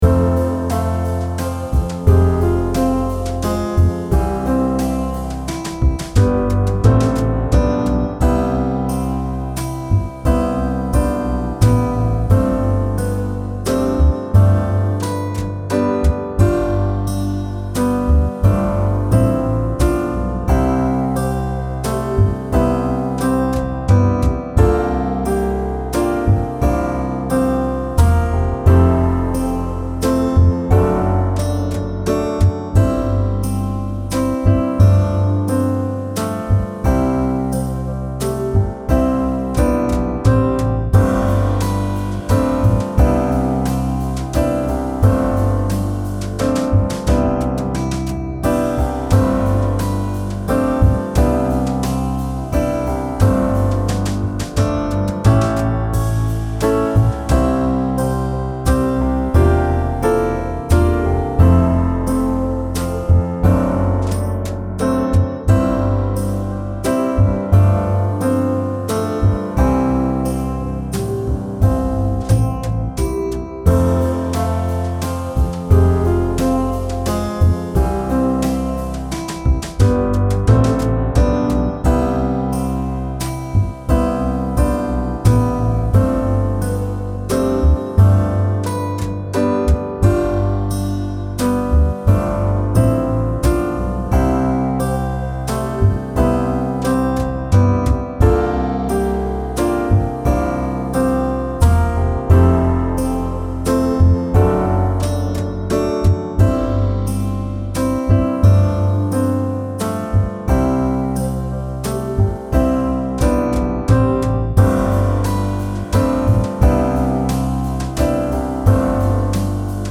Und hier könnt ihr das Playback herunterladen.